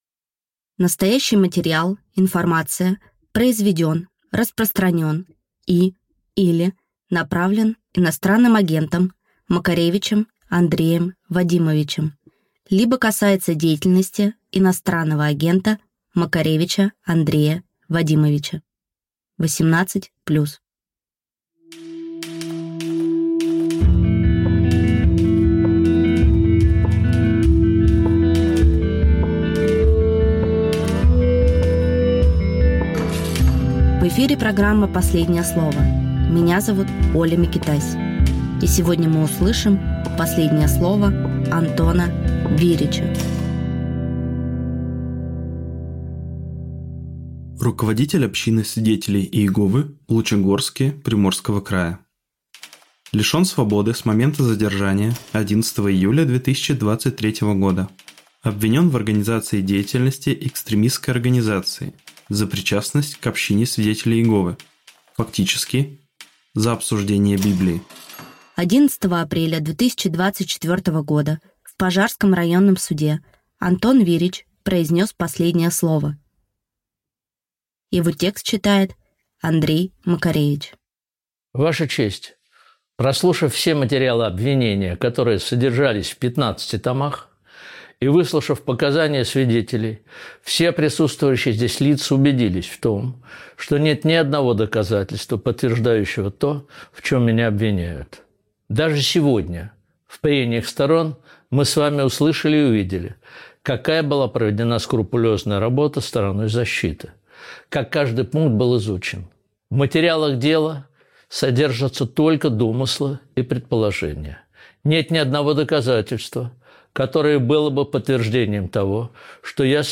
Андрей Макаревичмузыкант
Читает Андрей Макаревич